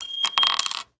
coin2.wav